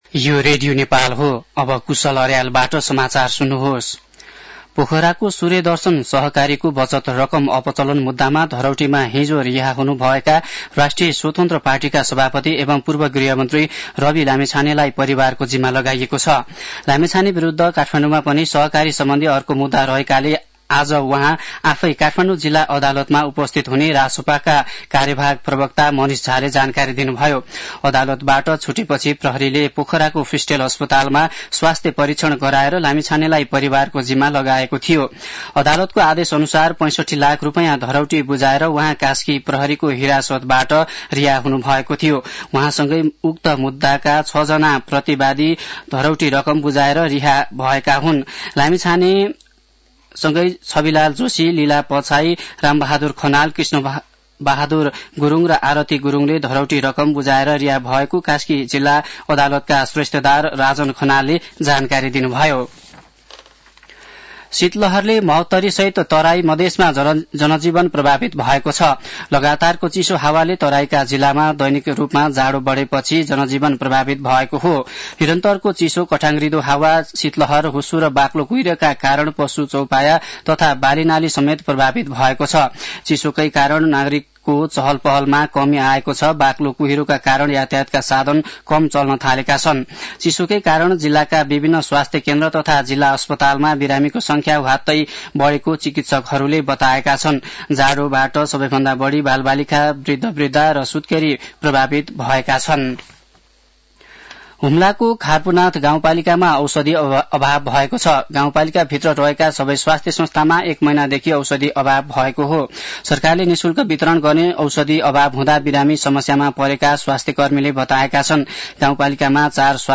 मध्यान्ह १२ बजेको नेपाली समाचार : २७ पुष , २०८१